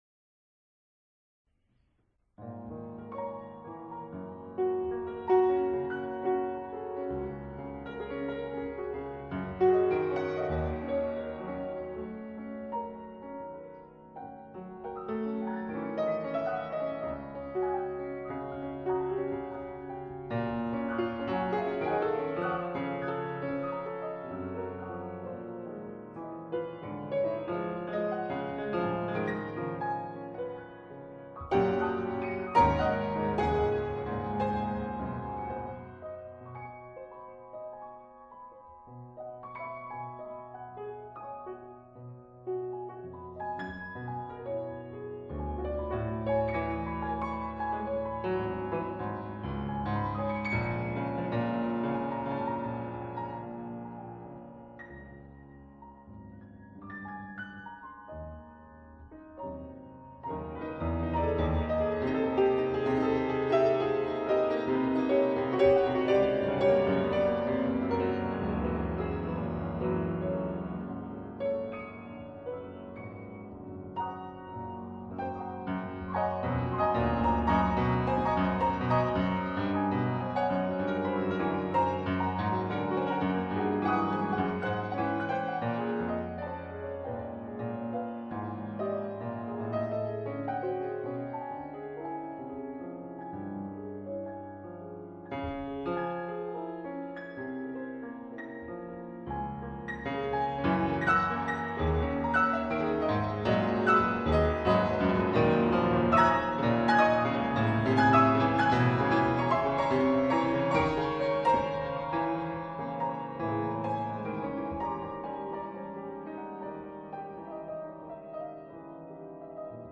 Music with complex focus interweaving)
For two pianos in abstract Coupling
Recording DDD of Centro Studi Assenza; jan. 2000